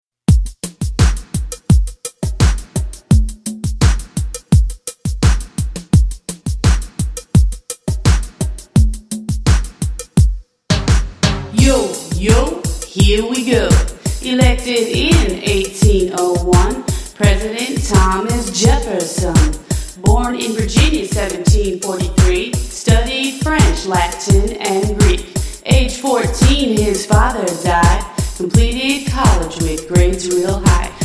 MP3 Demo Vocal Tracks